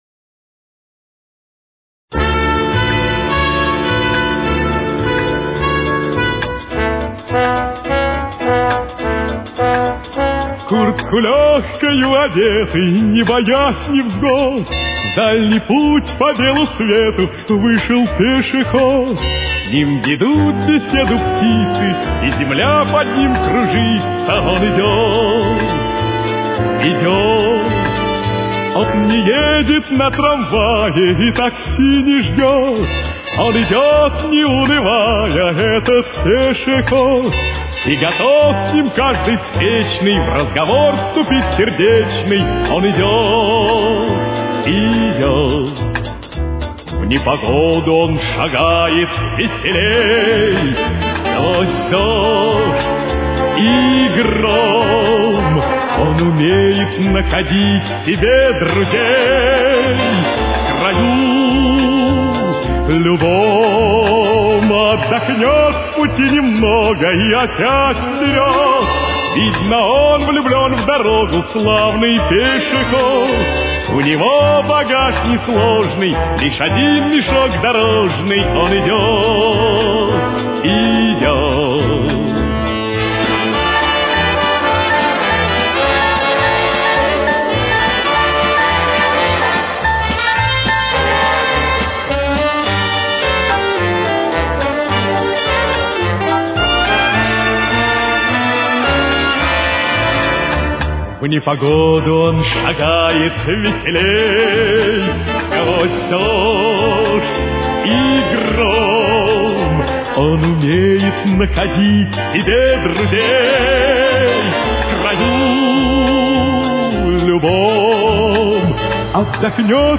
Ре минор. Темп: 107.